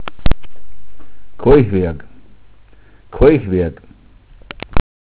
Eichstätt: Steinbruch im Buchtal und Kalkwerk Ecke Lüftenweg Mundart: koich_werg Internetsuchbegriff: Steinbruch im Buchtal Namenshäufigkeit: 85072 Eichstätt Zurück zu Flurnamen Eichstätt, bitte die Ochsen anklicken.